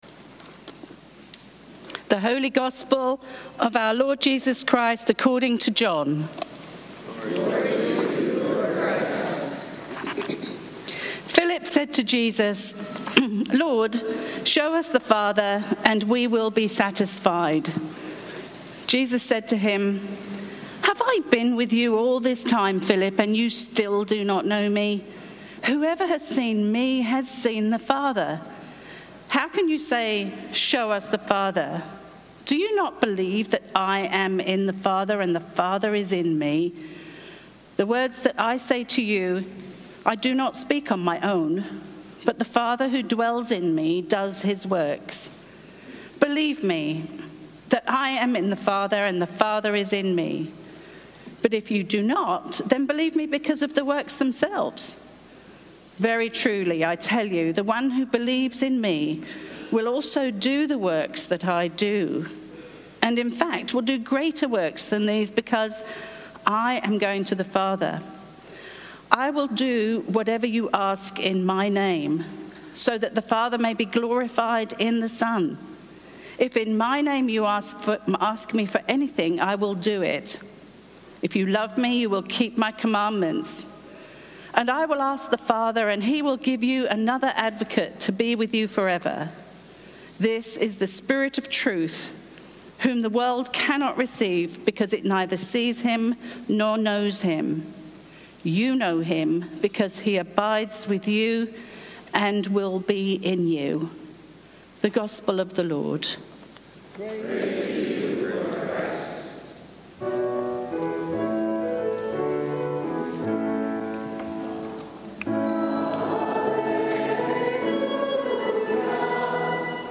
Sermons
St. Columba's in Washington, D.C.